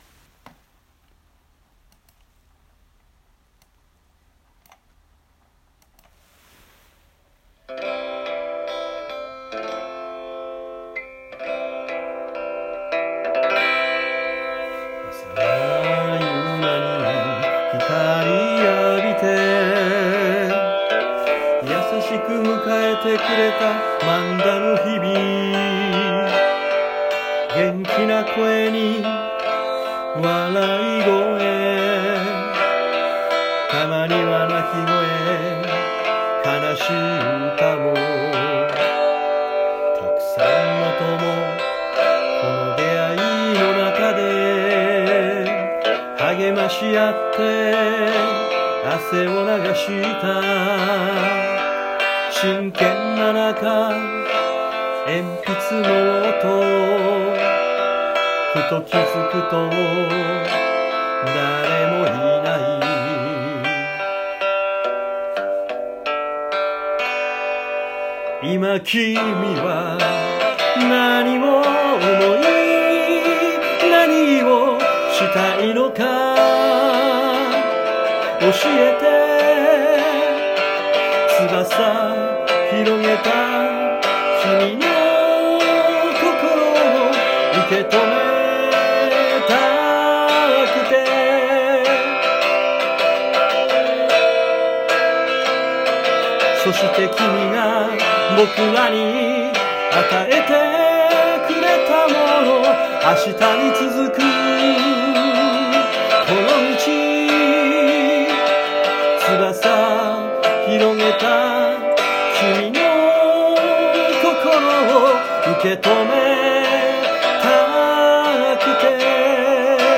このたび実は、いろんなところでがんばってくれた卒業生にむけた、はなむけの歌を作りました。
昔ちょっとかじったギターで。がたがたにしかできませんでしたが・・・。